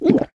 drink1.ogg